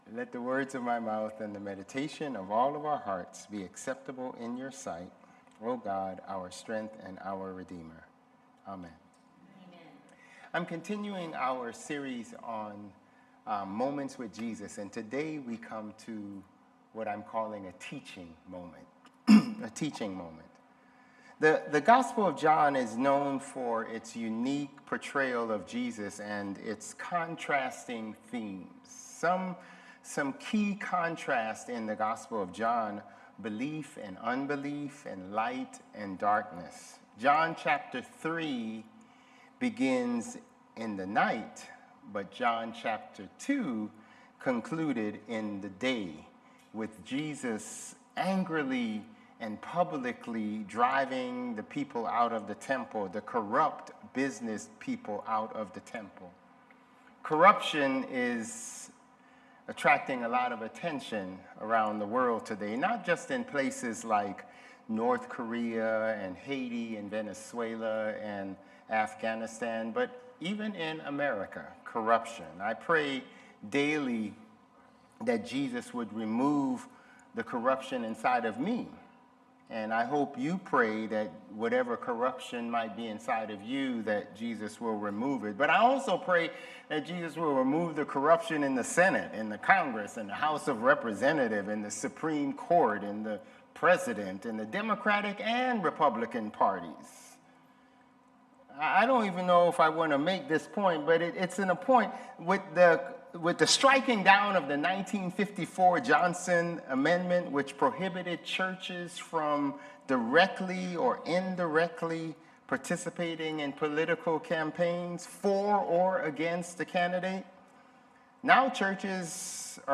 Sermons | Bethel Lutheran Church
July 13 Worship